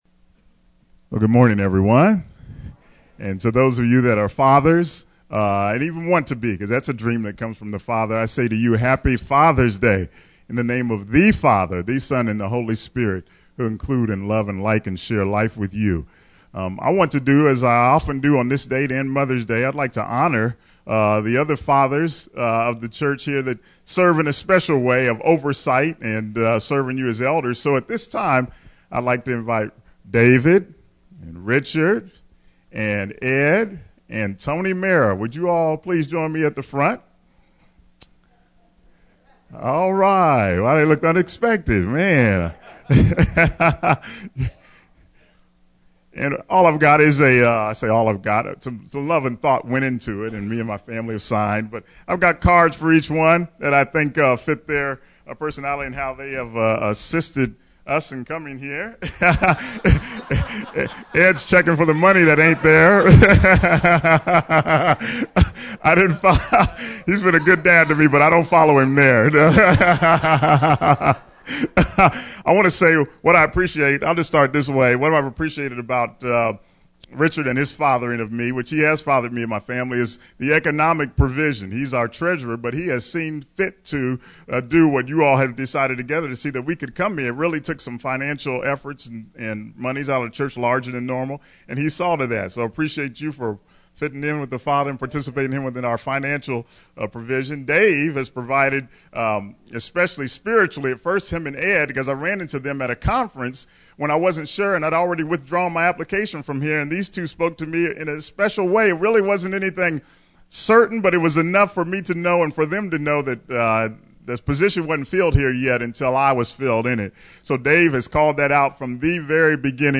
Sermon: Fathering God’s Way!